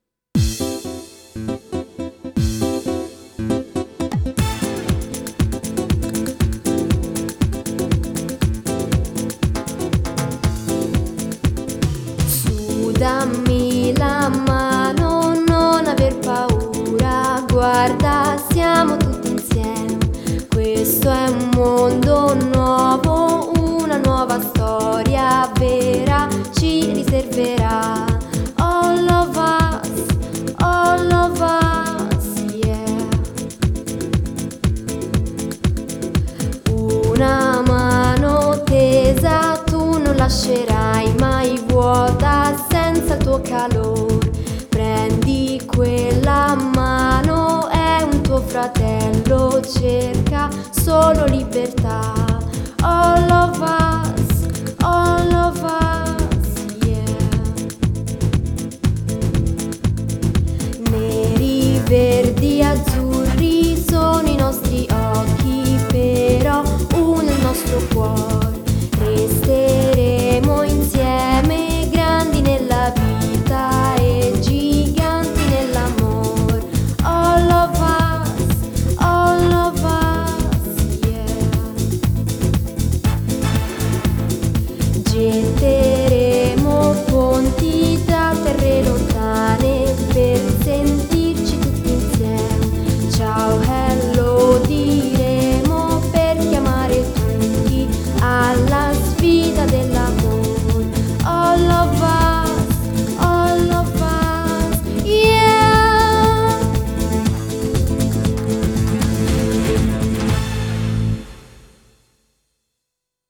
Canzoni originali